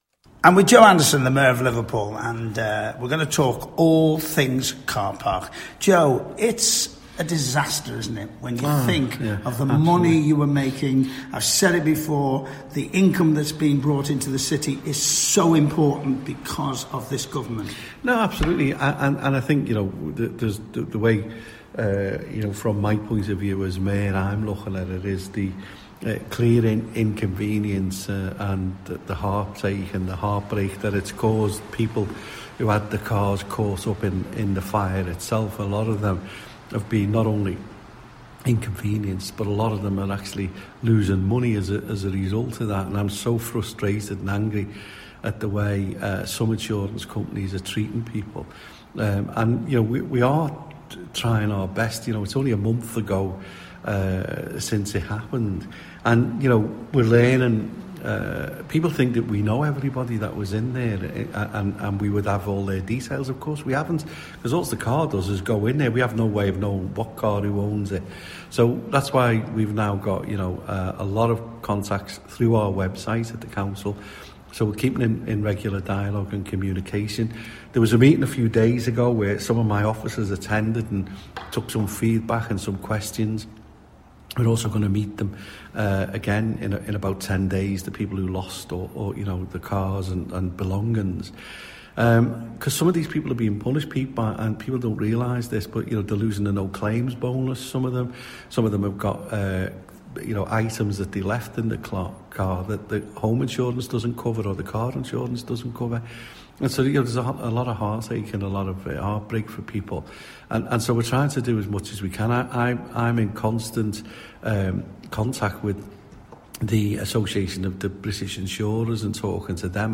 Mayor of Liverpool, Joe Anderson talking